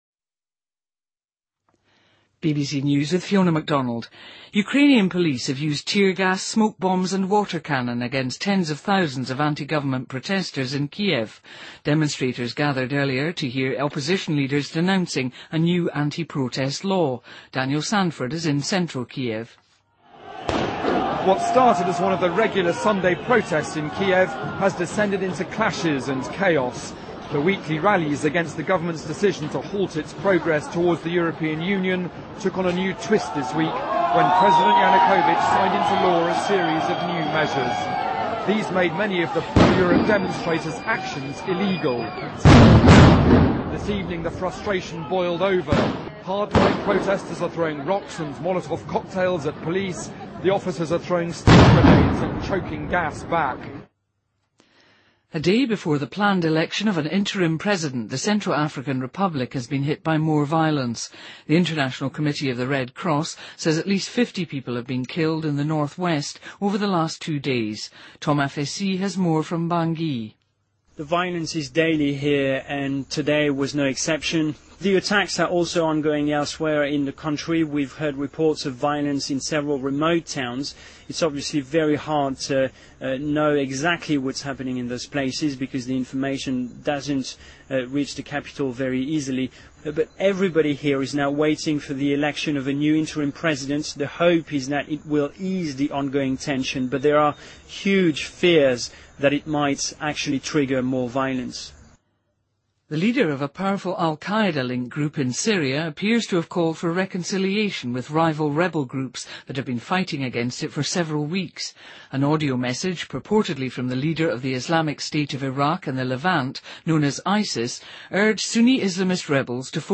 BBC news,2014-01-20